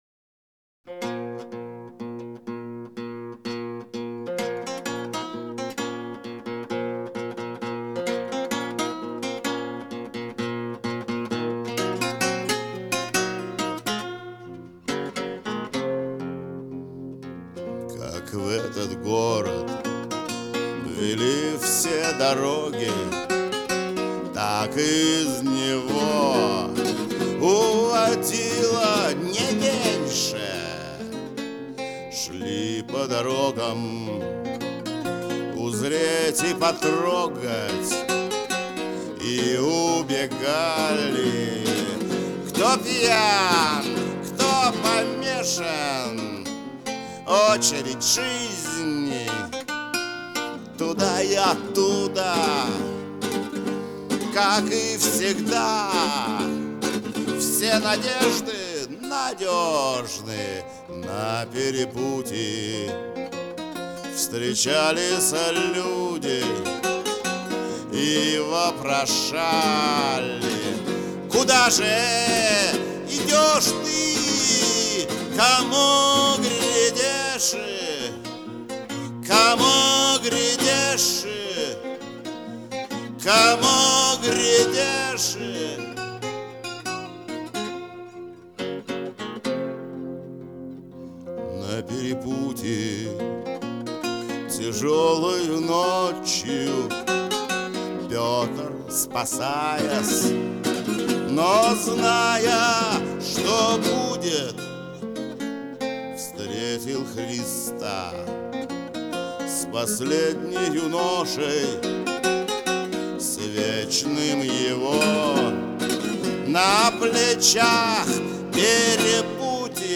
Am, 4/4